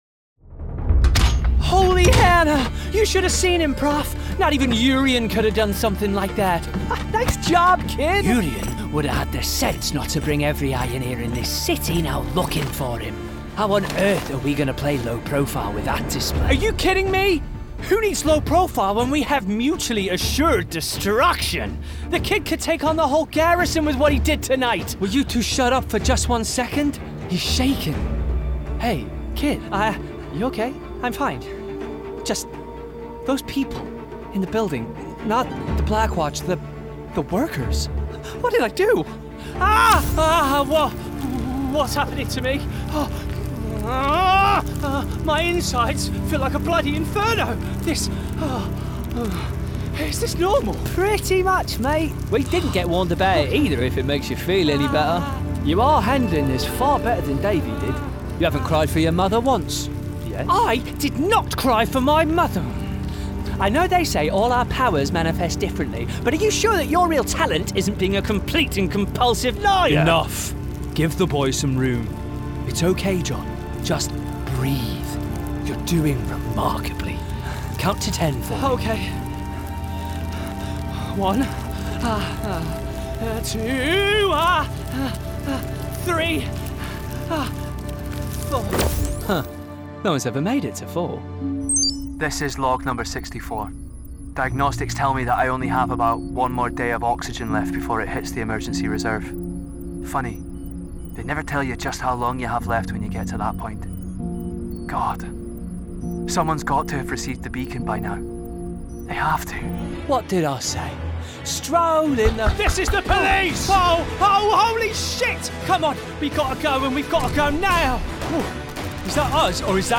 Video Game Reel
• Native Accent: Teesside
His versatility shines through as he seamlessly transitions from embodying the relatable everyman to a downtrodden soldier to a sinister villain, delivering each role with care and authenticity.